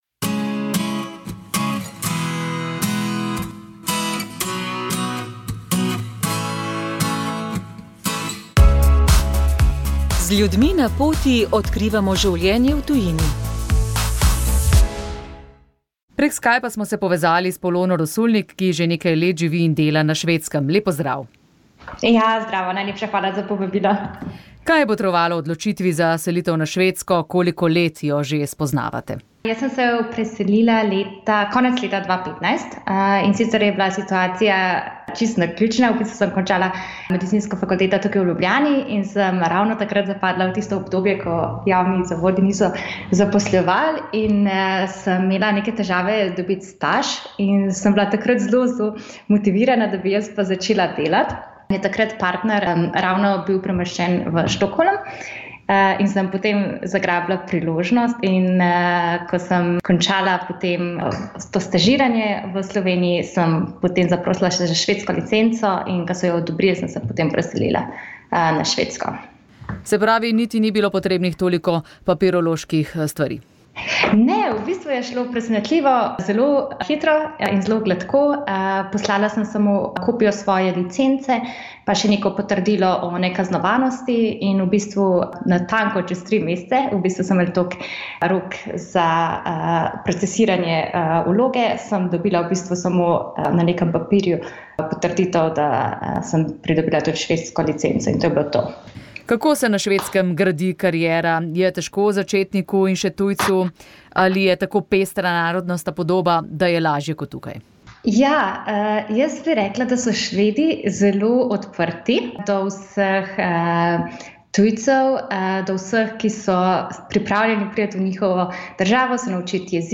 pogovor